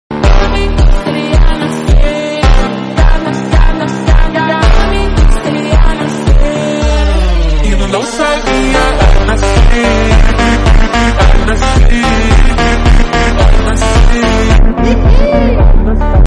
Slowed & Reverb